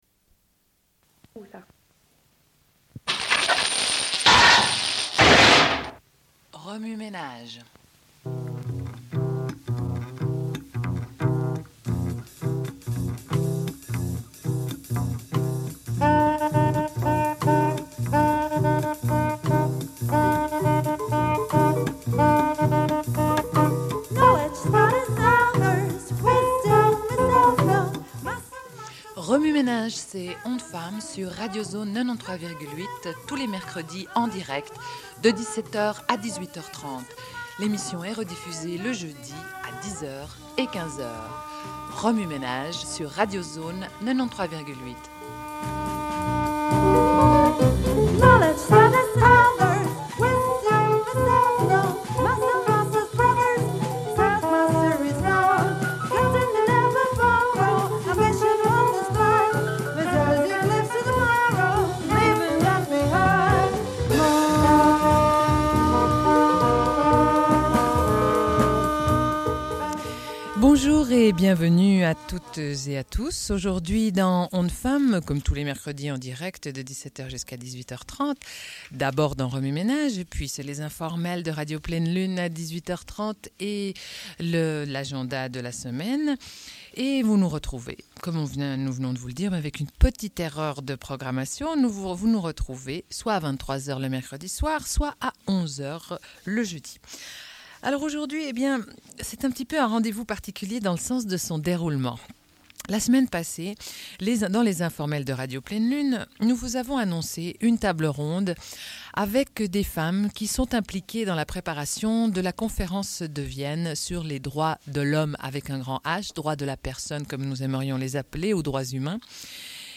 Une cassette audio, face A31:29